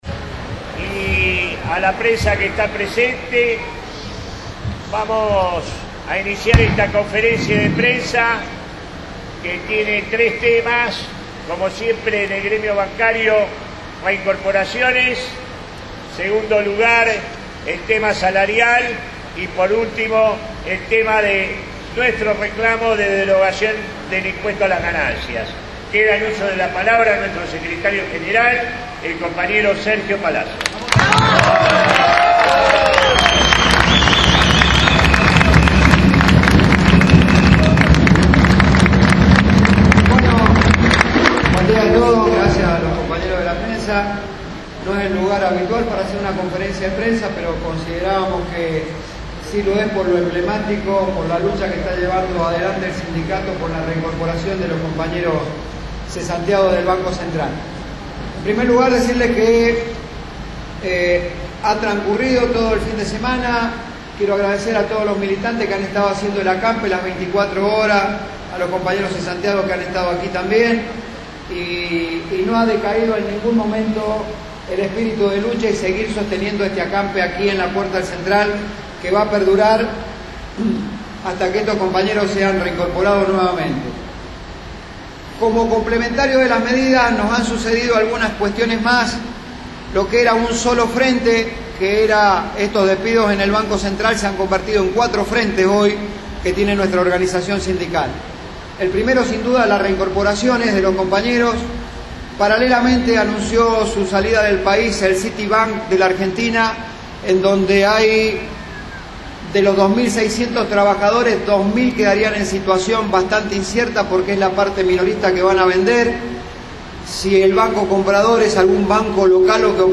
Tras varios días de acampe, la Asociación Bancaria llevó a cabo una conferencia de prensa frente a sede del Banco Central. El Secretario General, Sergio Palazzo, hizo hincapié en cuatro focos de lucha: reincorporaciones, venta del Citibank, paritarias e impuesto a las ganancias.
22-2-16-conf-de-prensa-bancarios-.mp3